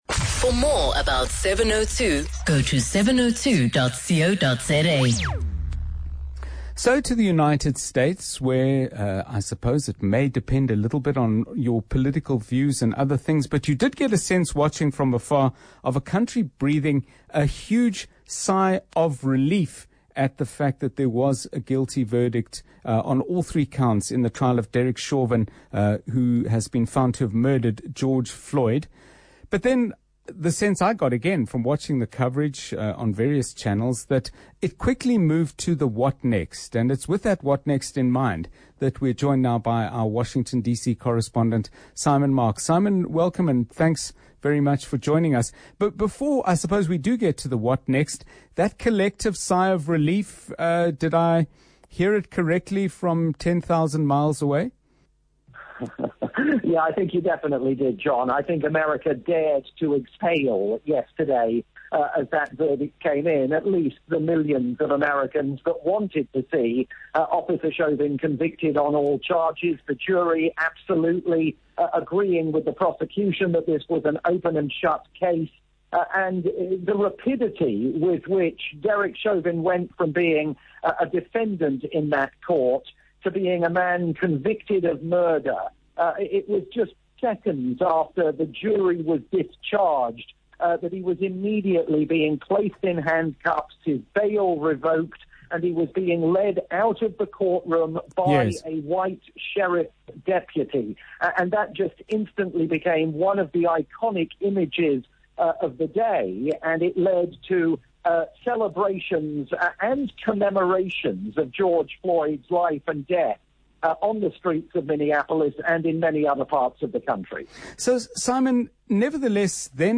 live report